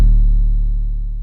TM808_2.wav